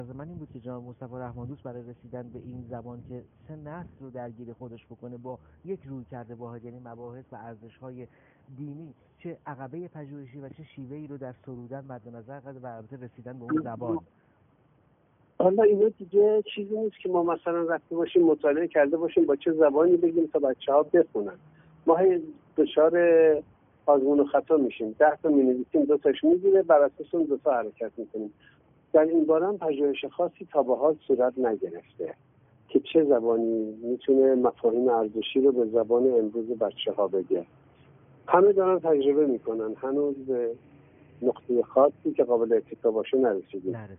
مصطفی رحماندوست در گفت‌وگو با ایکنا: